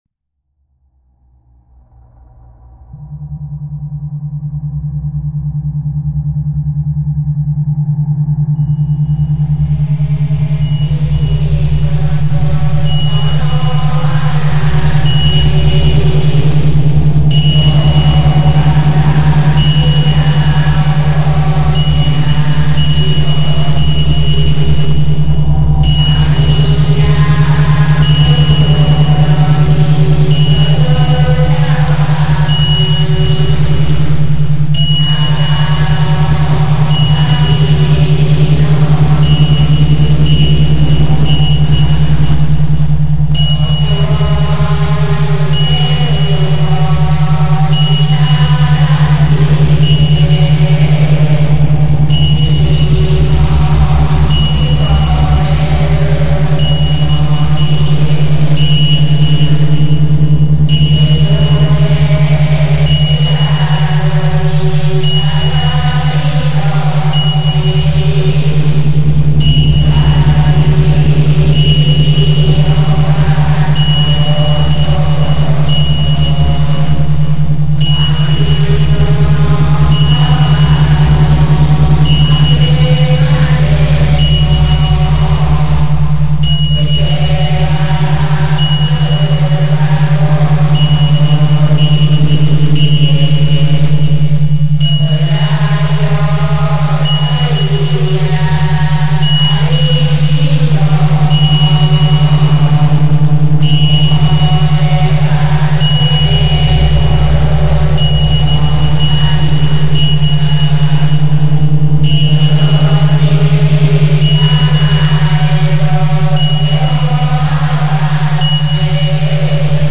Highly meditative/numbing-inducing work.